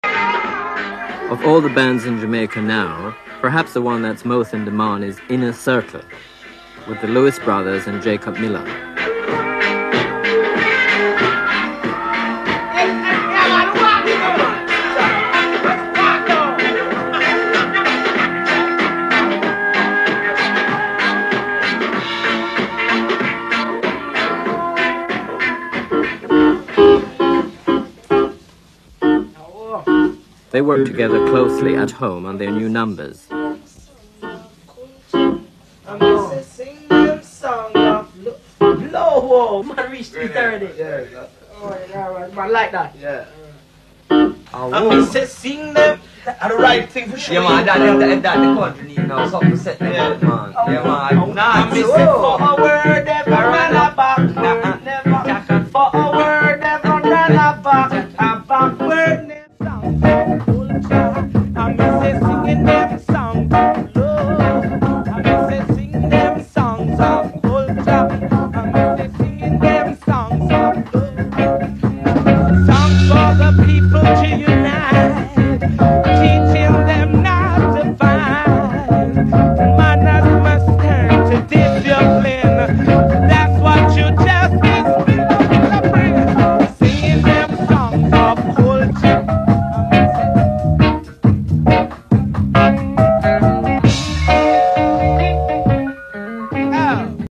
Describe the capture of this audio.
working on their material at home